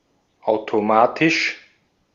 Ääntäminen
IPA: /ˌaʊ̯toˈmaːtɪʃ/